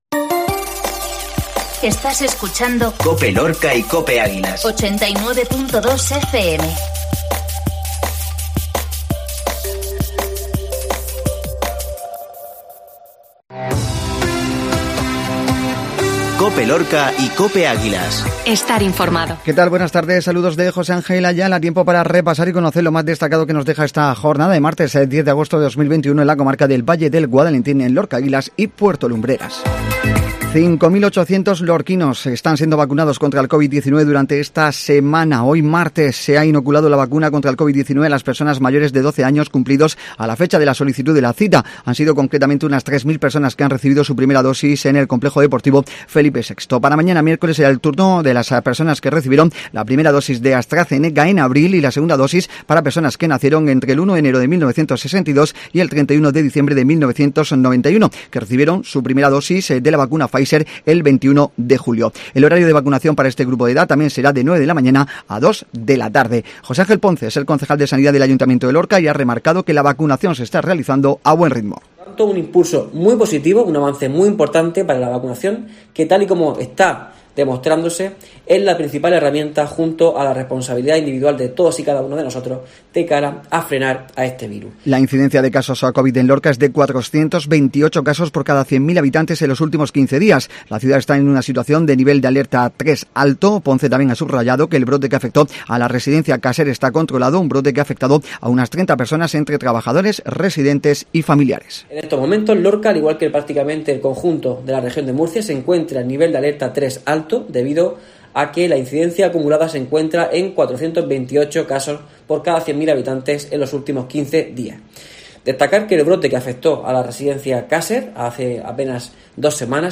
INFORMATIVO MEDIODÍA LORCA